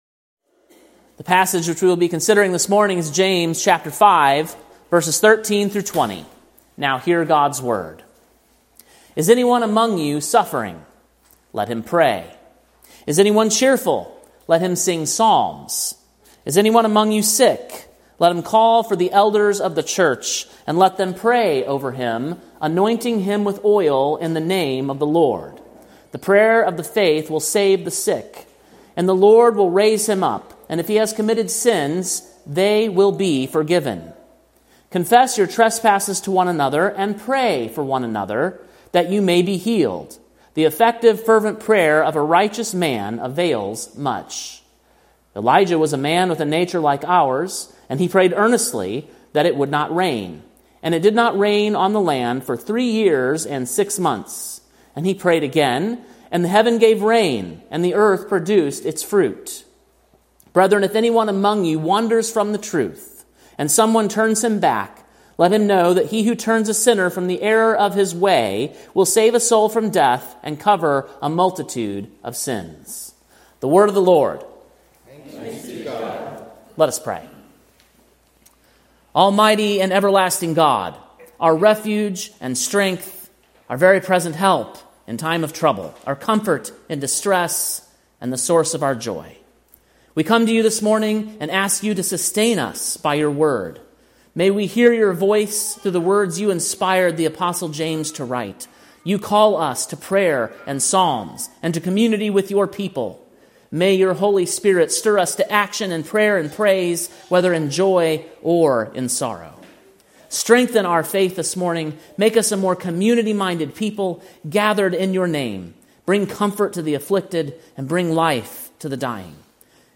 Sermon preached on May 25, 2025, at King’s Cross Reformed, Columbia, TN.